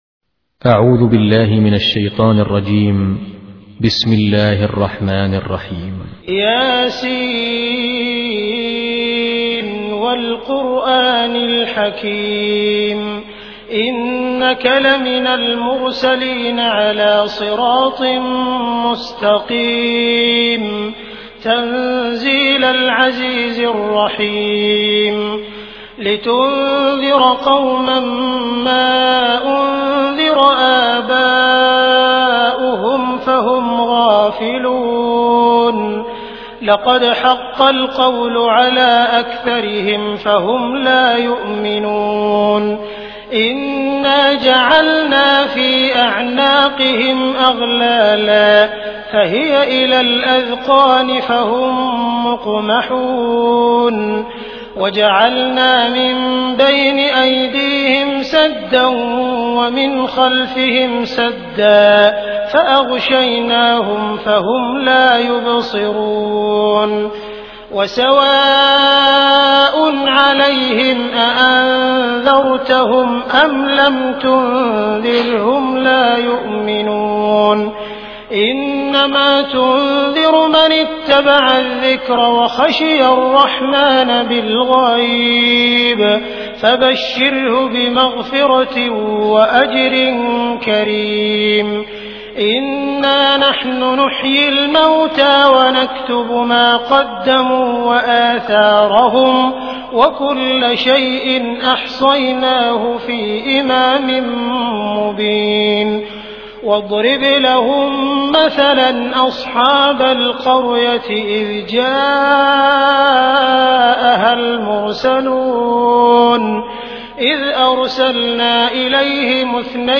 Di bawah ini kami membagikan sebuah murottal surat yasin dari 15 Qori’ yang bersuara merdu.
Ngaji Surat Yasin Abdurrahman As Sudais